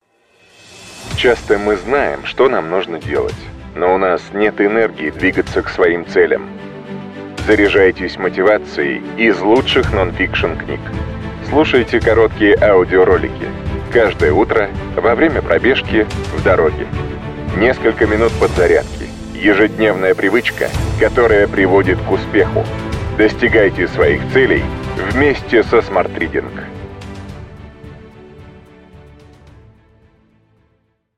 Аудиокнига Бойся, но действуй! Как превратить страх из врага в союзника.